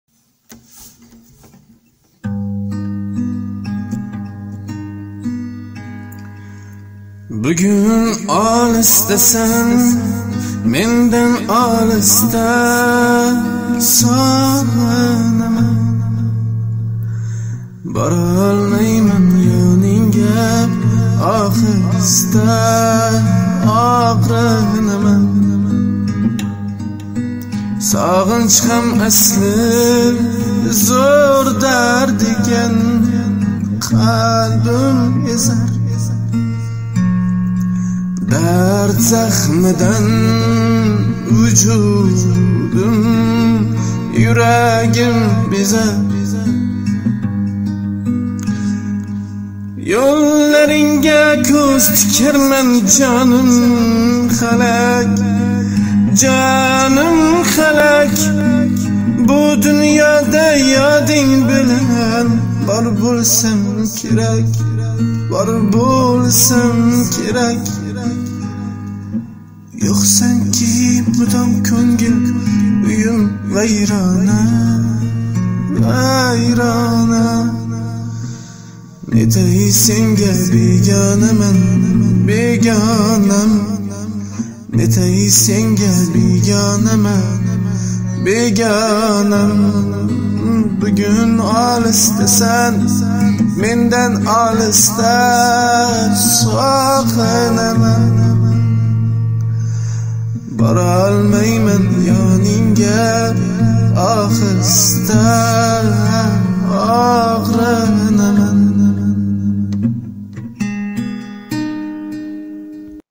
jonli ijro